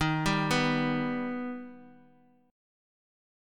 Eb+ Chord
Listen to Eb+ strummed